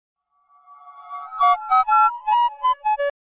Double Goofy Ahh Android Notification Sound Button - Free Download & Play